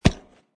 icegrass.mp3